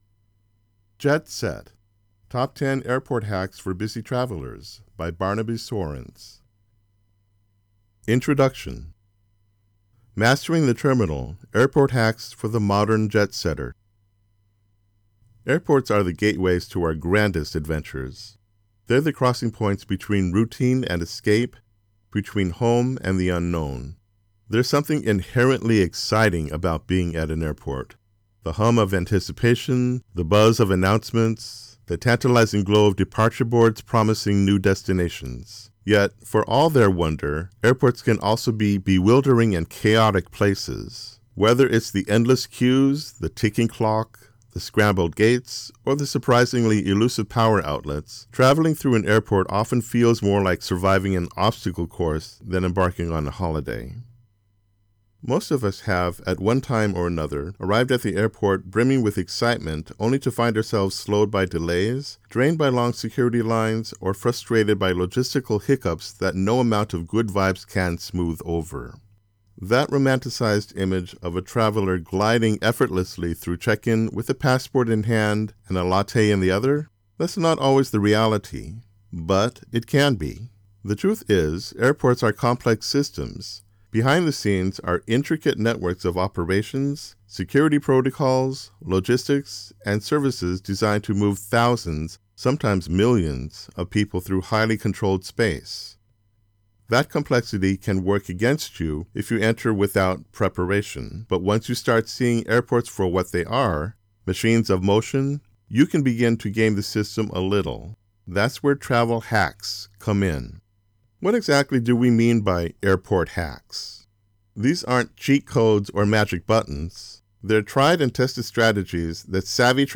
Standard American Male accent
Audiobook